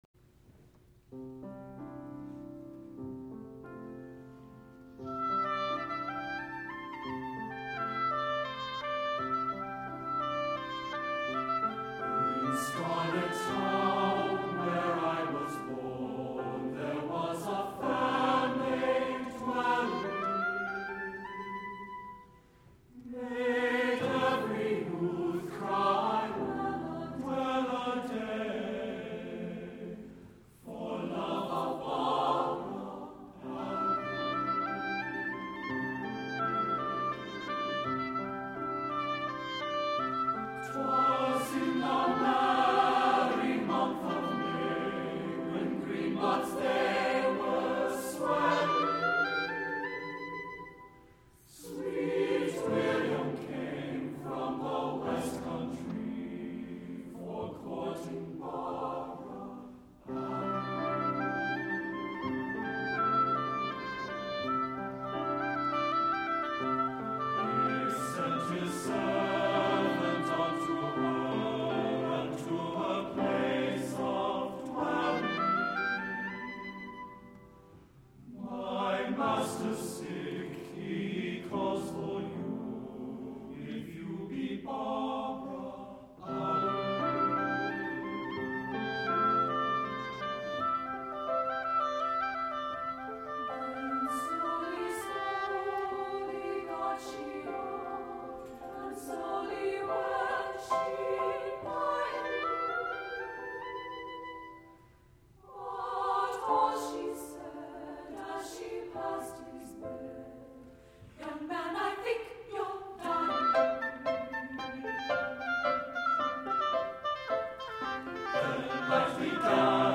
for SATB Chorus, Oboe, and Piano (2002)